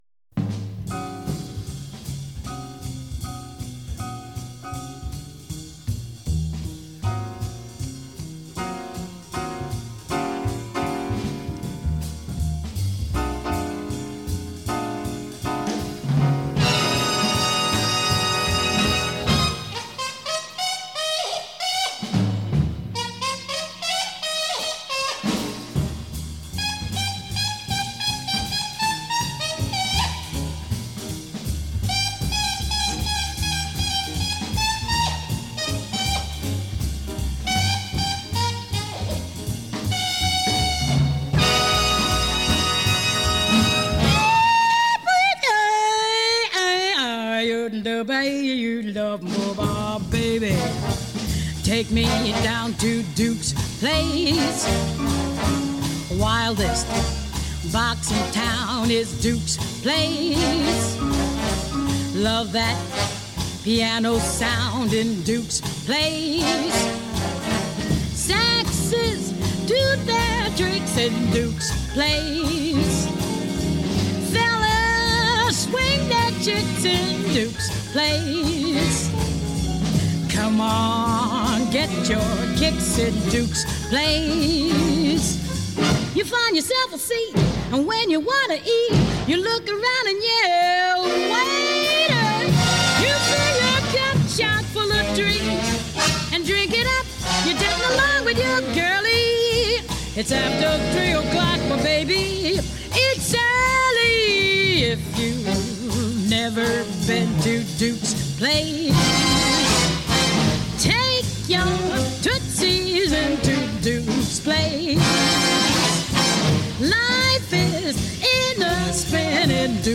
Jazz After Dark spans the gamut, from roots in boogie-woogie, blues, and ragtime through traditional and straight-ahead jazz, soul jazz, bossa nova, and more.